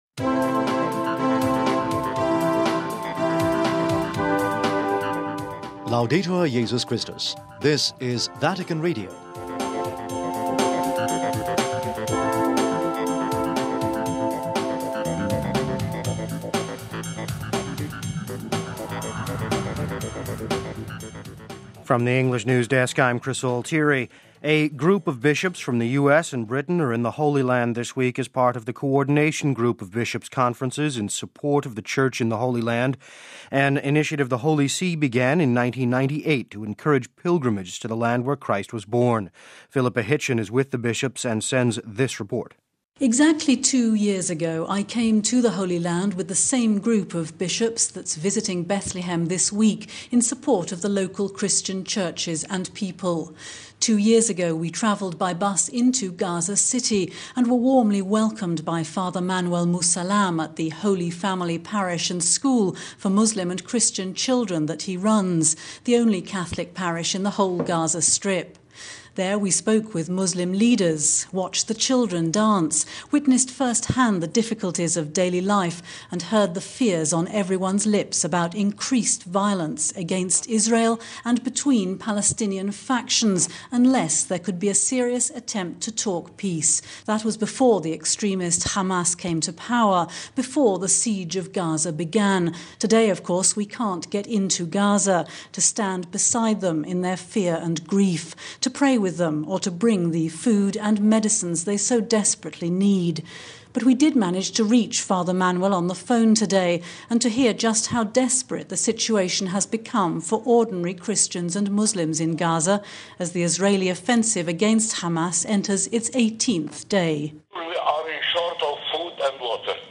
and sends us this report…